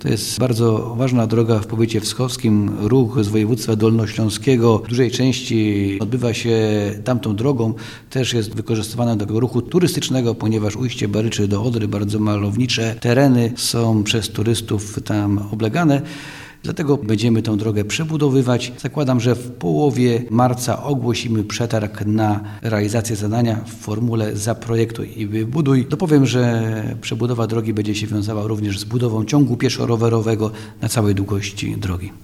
powiedział Andrzej Bielawski, wschowski starosta